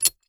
open_screw.mp3